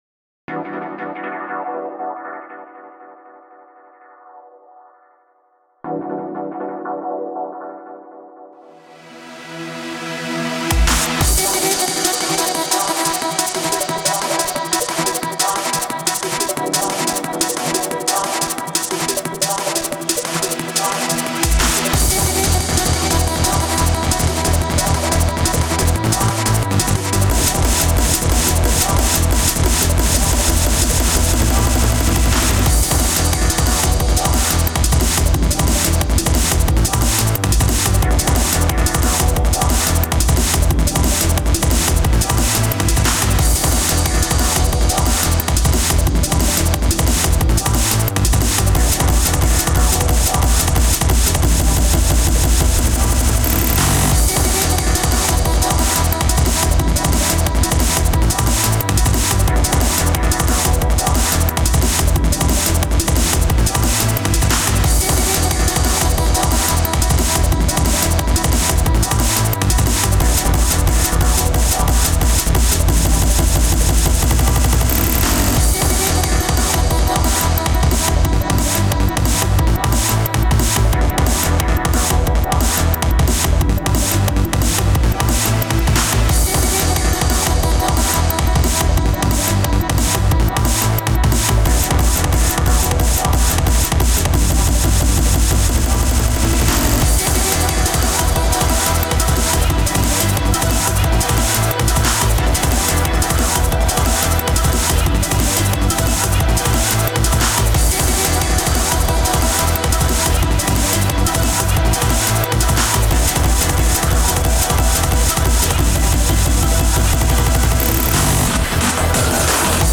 Drum’n Bass mix
(Distorted Loud mix)